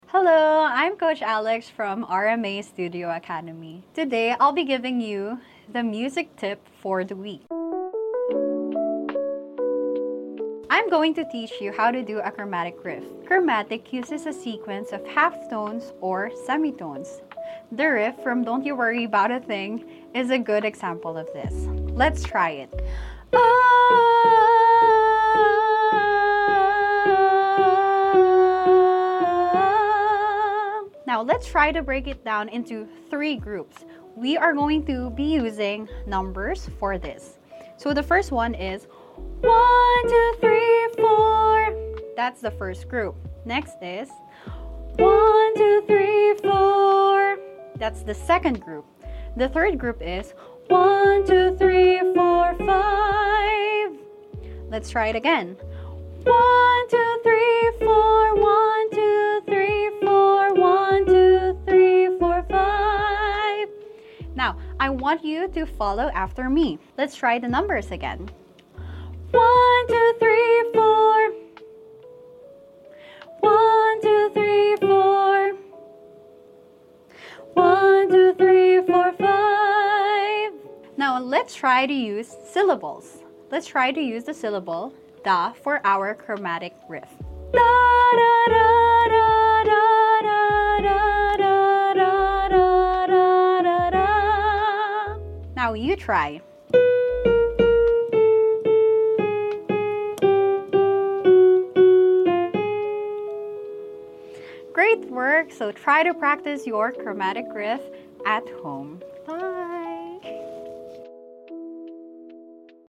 chromatic riffs